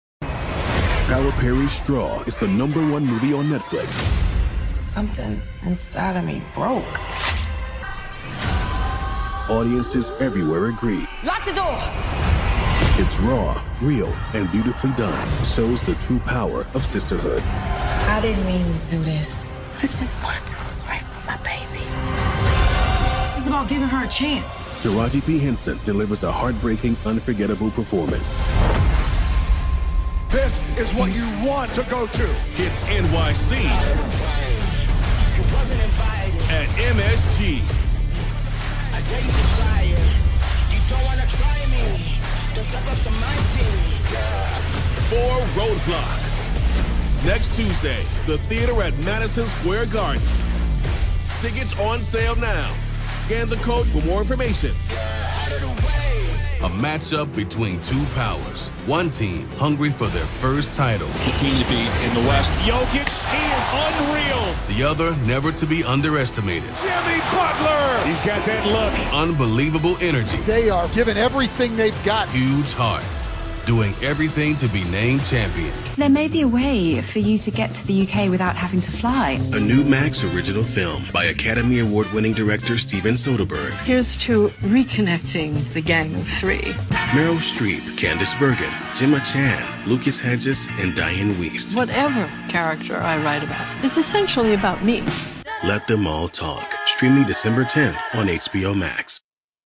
Dynamic, Epic, Serious.
Promo